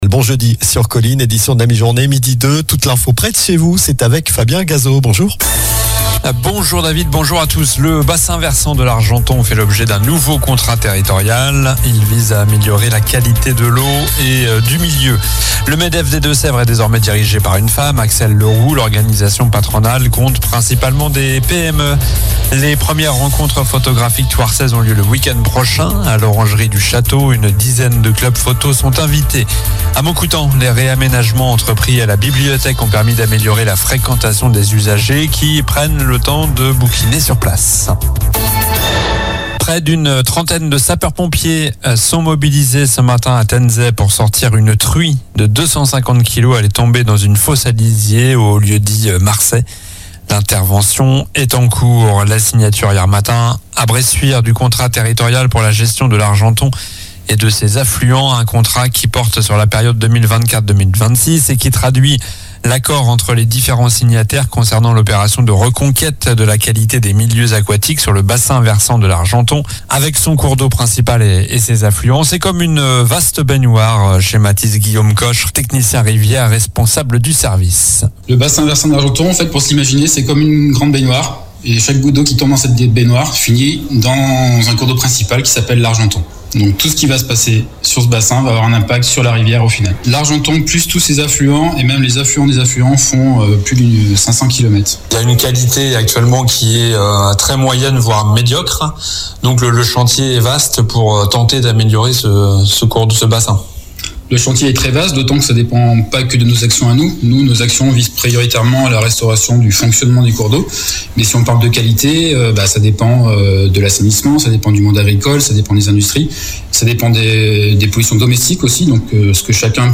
Journal du jeudi 18 avril (midi)